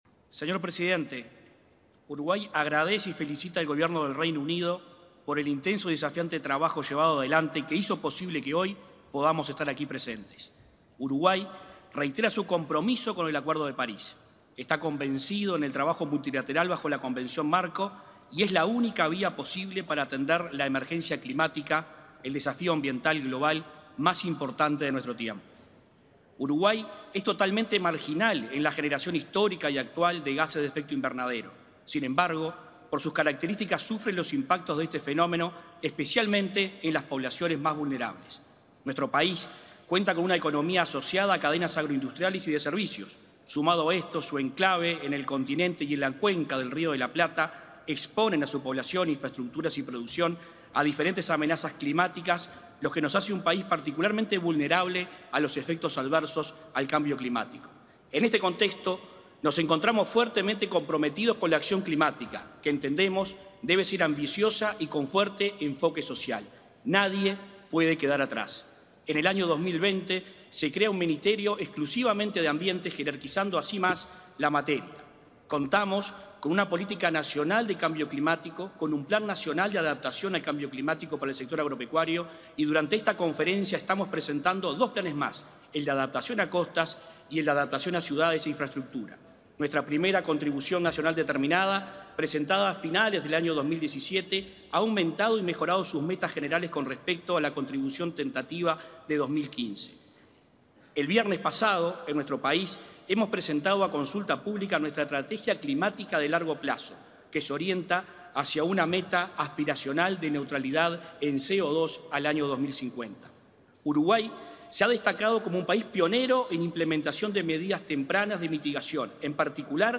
Palabras del ministro de Ambiente, Adrián Peña
El ministro de Ambiente, Adrián Peña, realizó su intervención el martes 9, en la 26.ª Conferencia de las Naciones Unidas sobre Cambio Climático